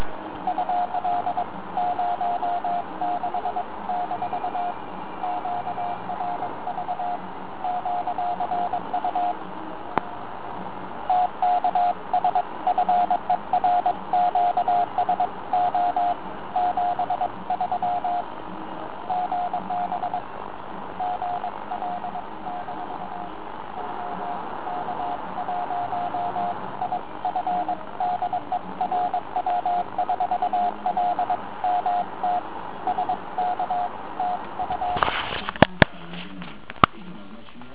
To rádio, co slyšíte v pozadí nepochází z Rozkmita, ale je to normální FM rádio, které v pozadí hrálo.